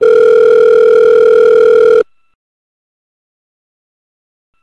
sound effects
ringing phone